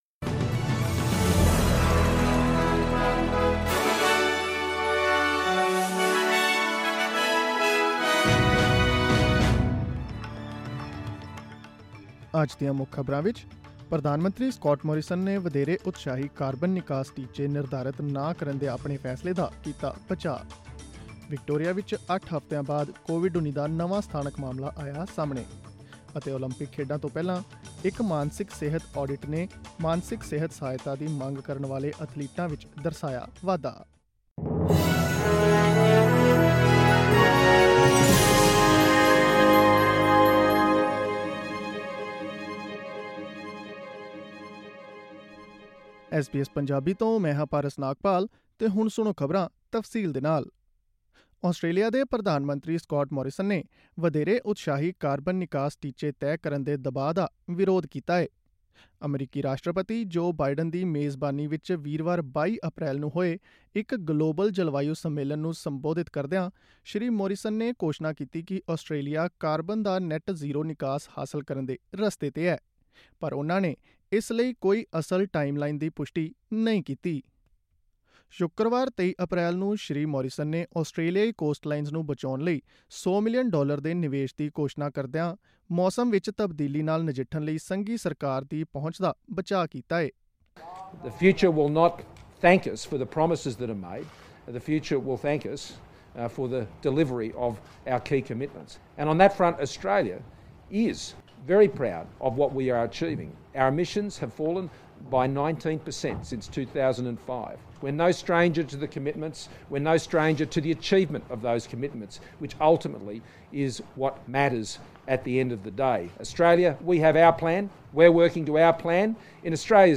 Australian News in Punjabi: Victoria's eight-week run without a locally-acquired case of COVID-19 comes to an end | SBS Punjabi
Click on the audio icon in the picture above to listen to the news bulletin in Punjabi.